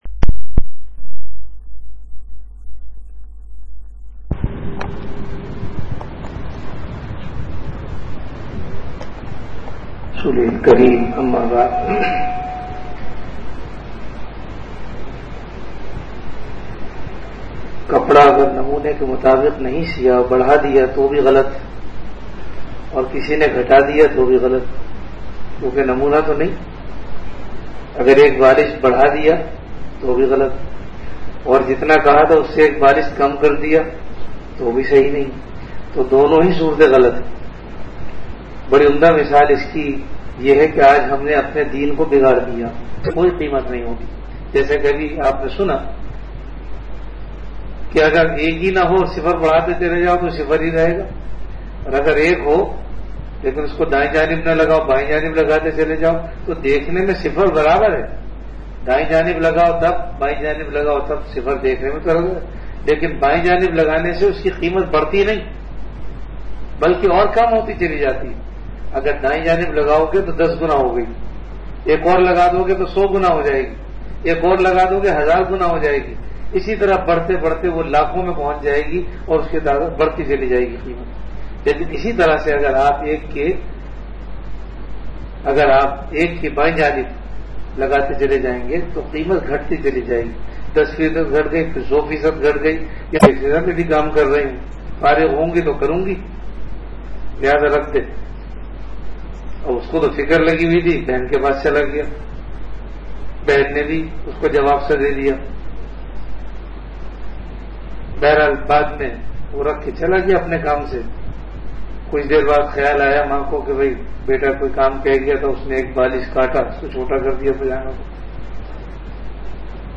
Majlis-e-Zikr · Home Allah(SWT
Event / Time After Isha Prayer